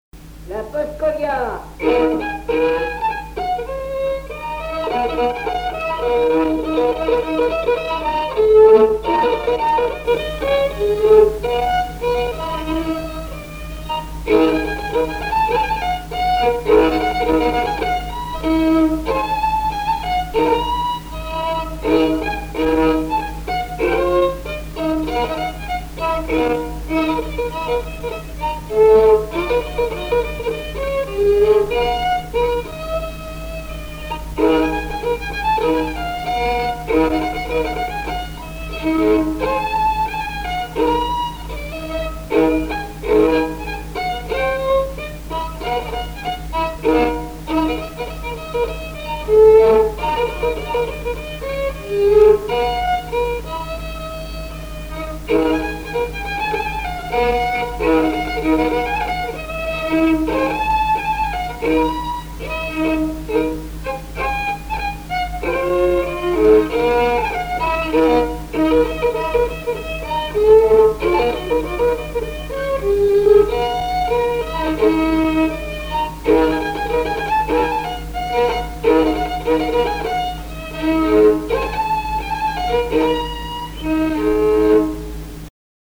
danse : paskovia
Répertoire du violoneux
Pièce musicale inédite